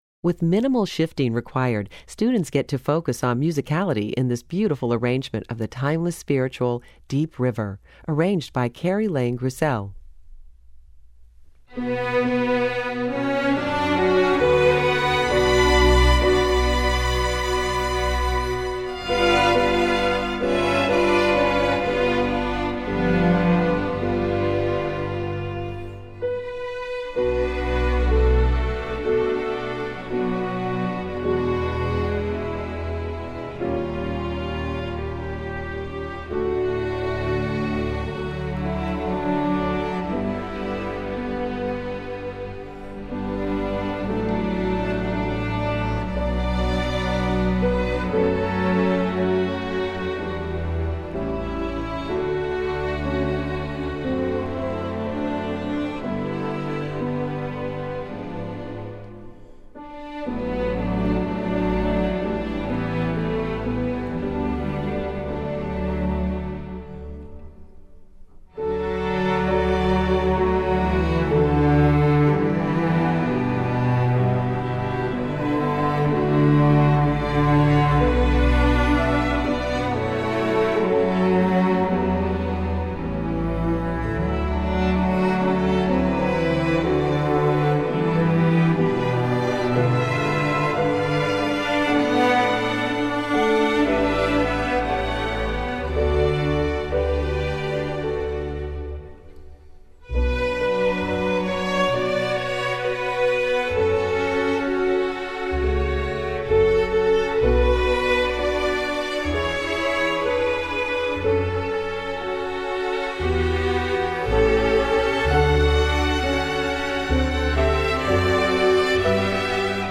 Composer: African-American Spiritual
Voicing: String Orchestra